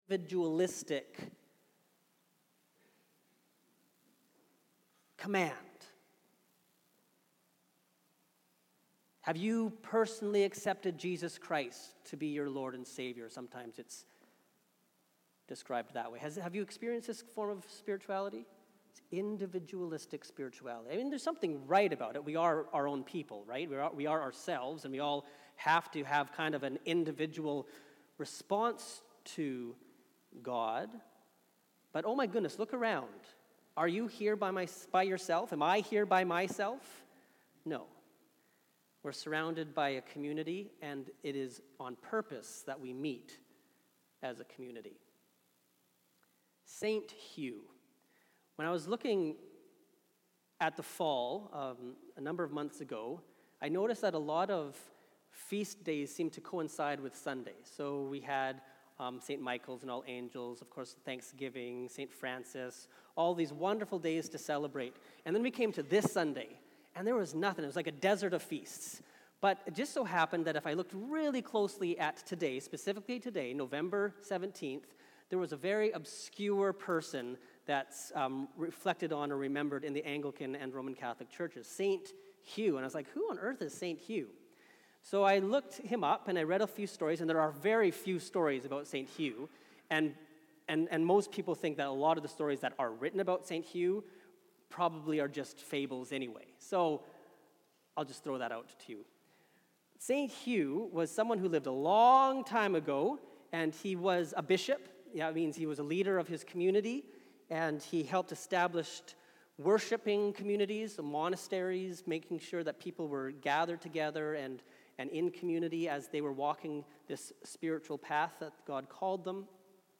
Sermons | St. Dunstan's Anglican